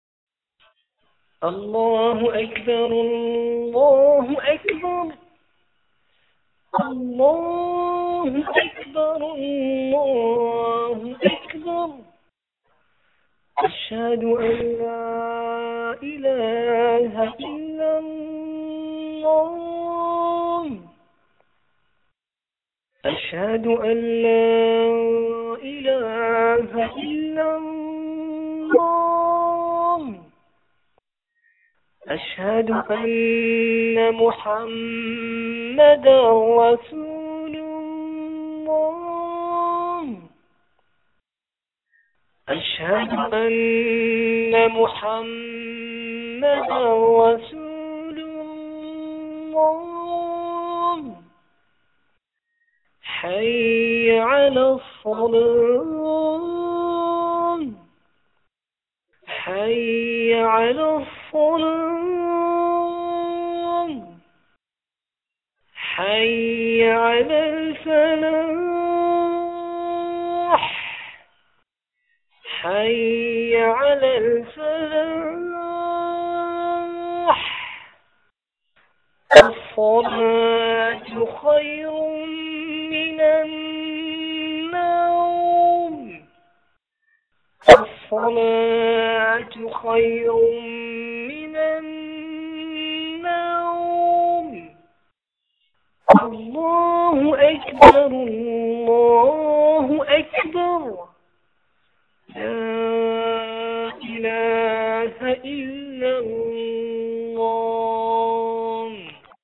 1. Adhan du Subh de la Mosqu�e BelleFontaine